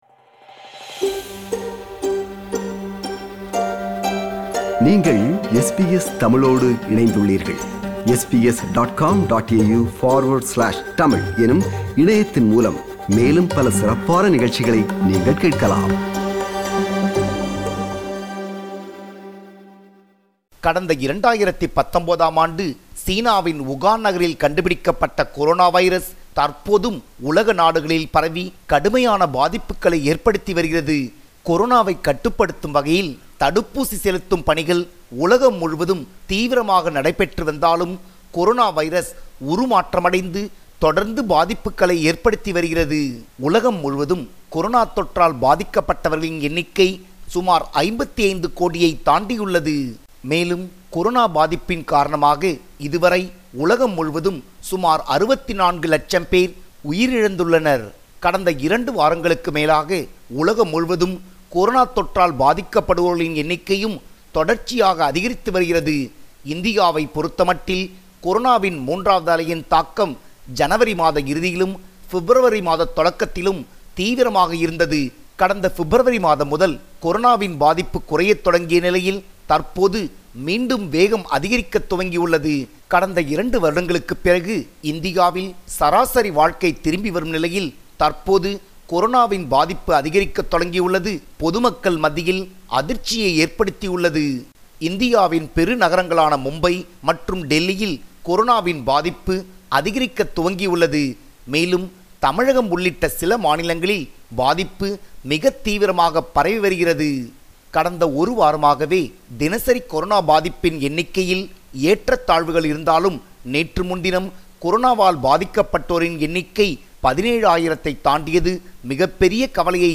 SBS Tamil